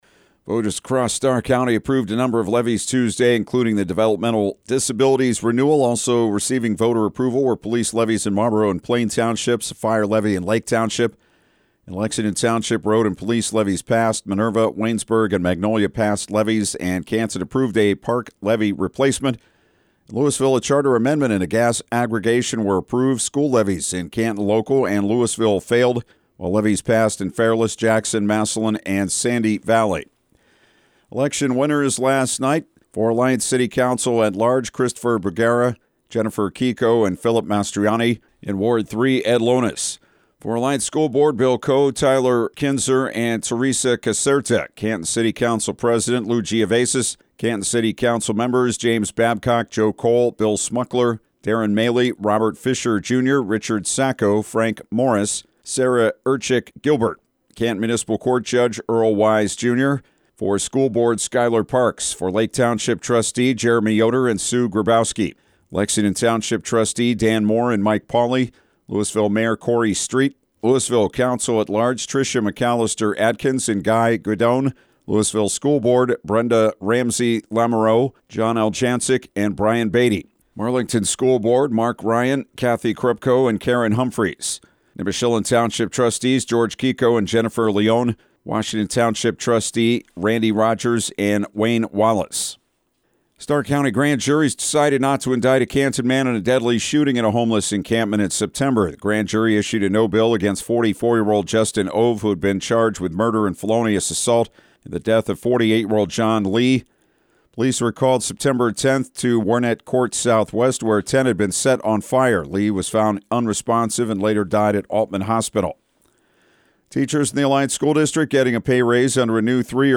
Morning News
6am-news-05.mp3